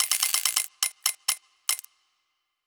Prize Wheel Spin 2 (short).wav